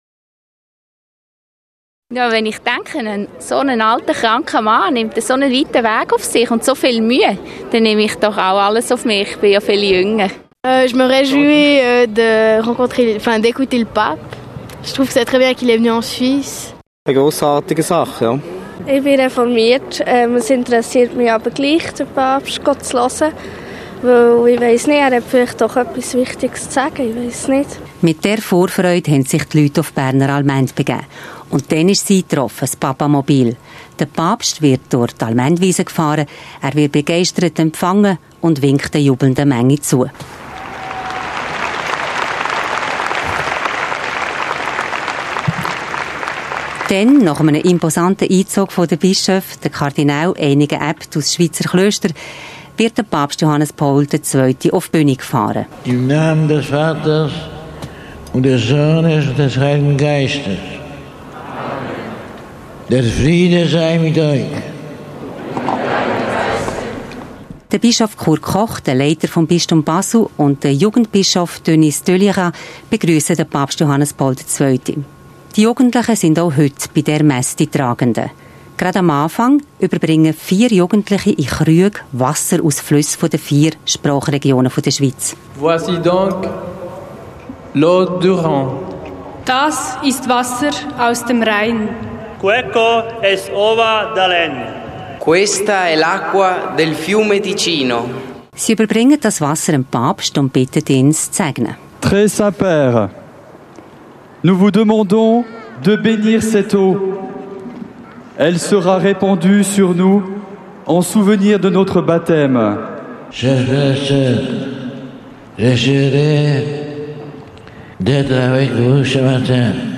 Papst Johannes Paul II. besuchte im Juni das reformierte Bern. 70’000 Menschen aus dem In- und Ausland kamen am Sonntag zur Messe unter freiem Himmel.